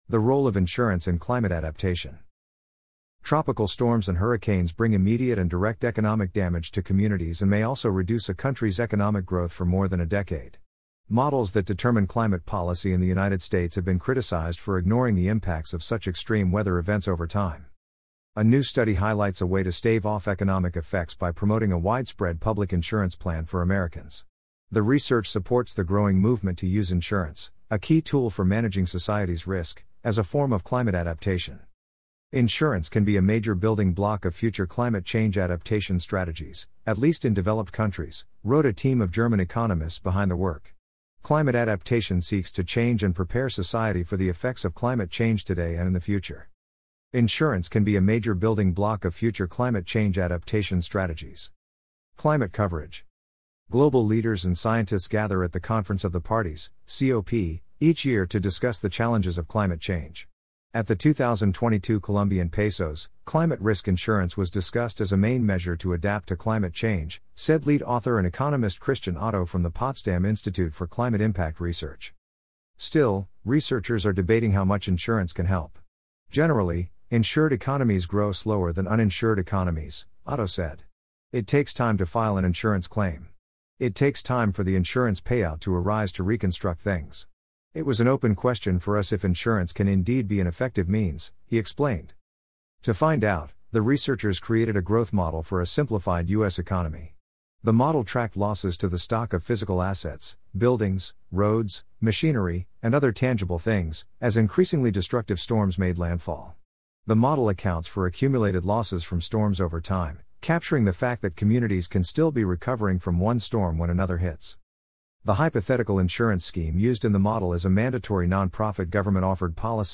amazon_polly_74170.mp3